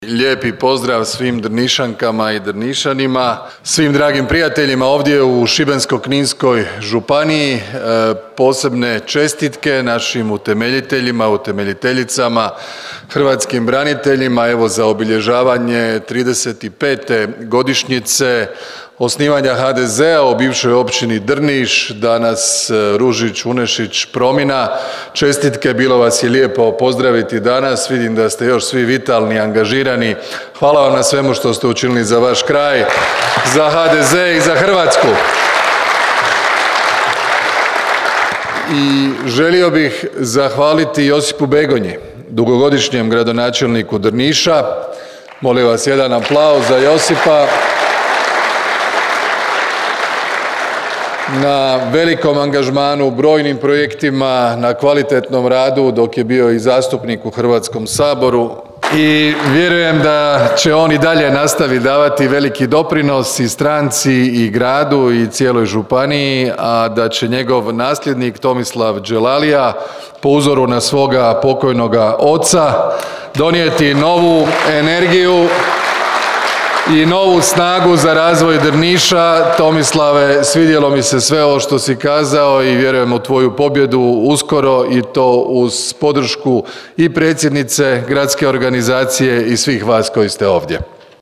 Drniški HDZ proslavio 35. obljetnicu osnutka te održao predizborni skup
Predsjednik HDZ-a i premijer Andrej Plenković zahvalio se svima koji su na ovom području osnovali HDZ te na tri mandata djelovanja gradonačelniku Josipu Begonji: